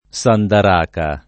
vai all'elenco alfabetico delle voci ingrandisci il carattere 100% rimpicciolisci il carattere stampa invia tramite posta elettronica codividi su Facebook sandaraca [ S andar # ka ] e sandaracca [ S andar # kka ] → sandracca